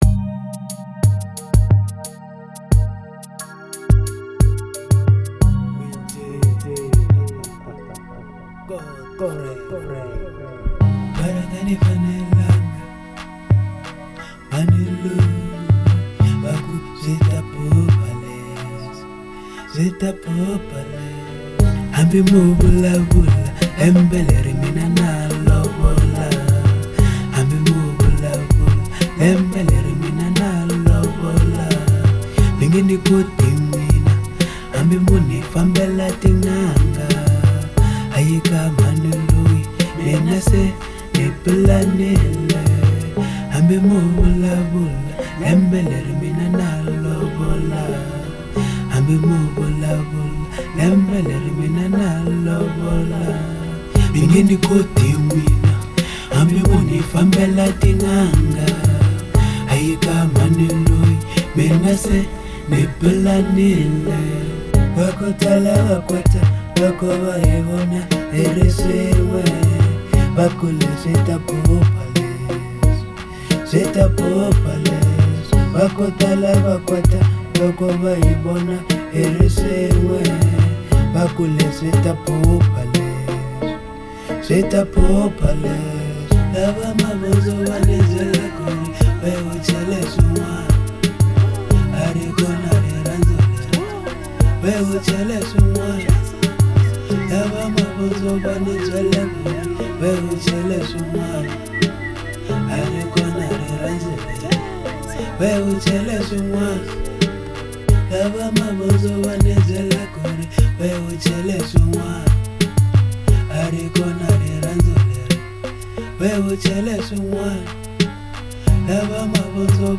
04:08 Genre : RnB Size